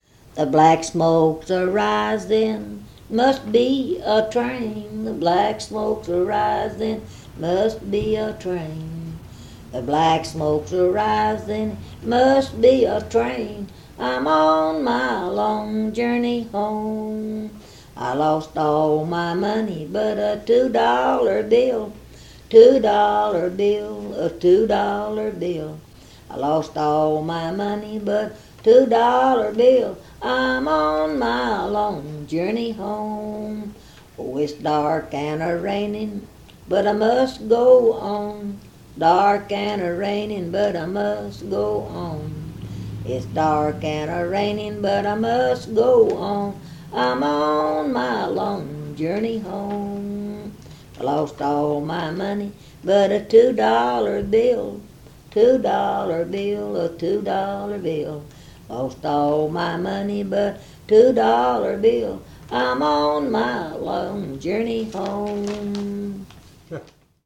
KEYWORDS: poverty homesickness loneliness train travel lyric nonballad